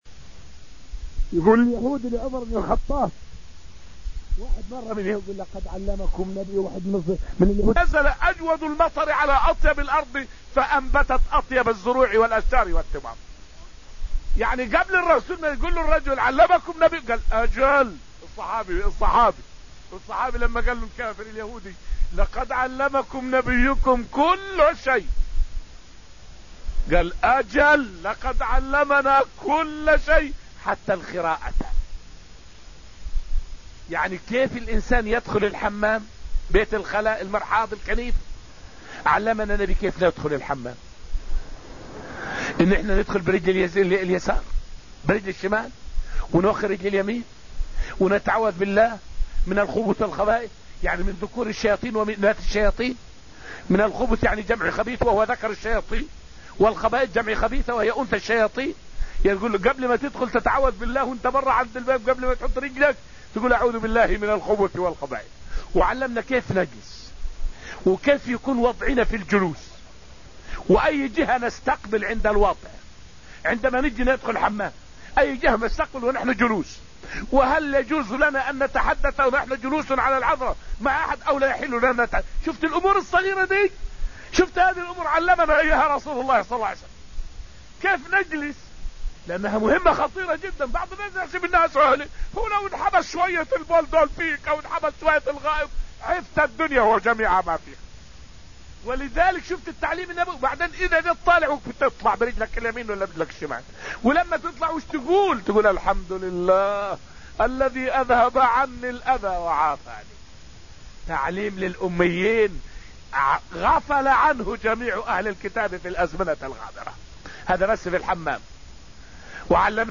فائدة من الدرس الثالث عشر من دروس تفسير سورة الحديد والتي ألقيت في المسجد النبوي الشريف حول تعاليم الإسلام الكافية الشافية.